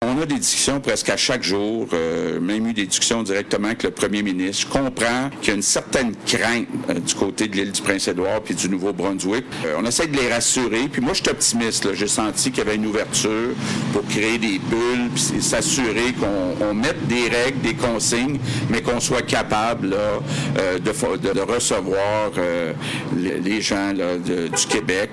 Point de presse du premier ministre François Legault accompagné de la ministre Marie-Ève Proulx à Carleton-sur-Mer